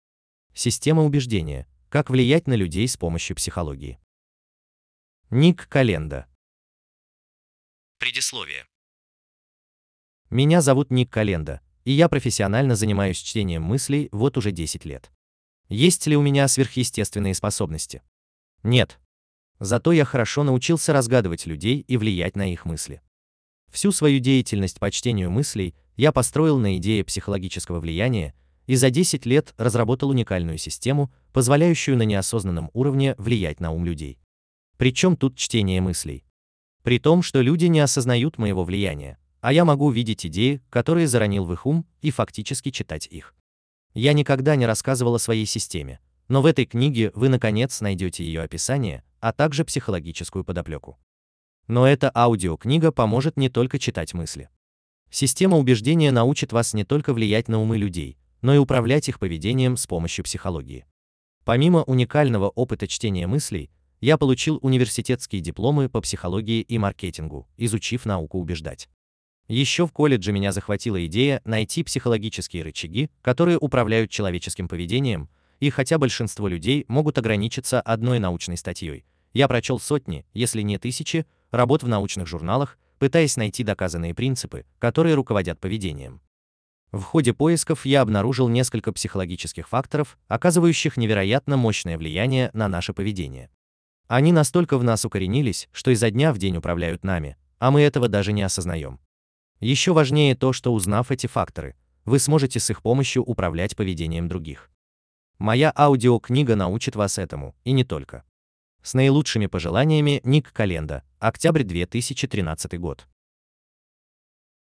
Аудиокнига Система убеждения: Как влиять на людей с помощью психологии | Библиотека аудиокниг